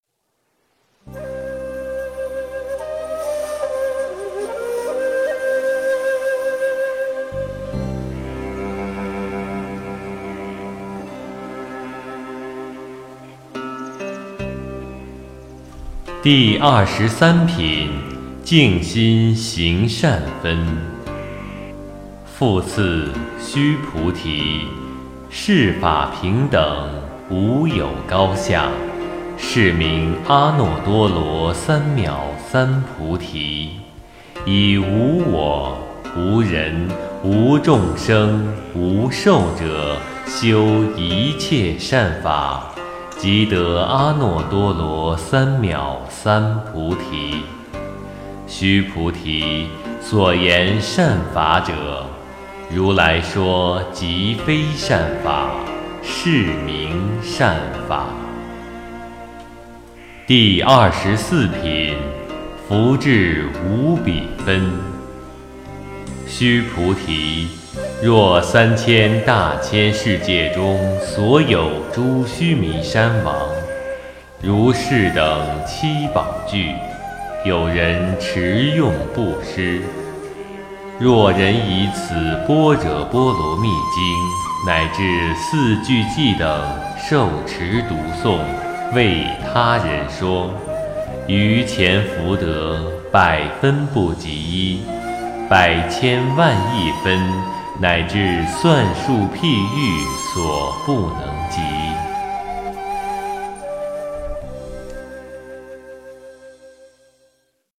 诵经
佛音 诵经 佛教音乐 返回列表 上一篇： 94.苦行的次第 下一篇： 七秀坊 相关文章 妙法莲华经观世音菩萨普门品--如是我闻 妙法莲华经观世音菩萨普门品--如是我闻...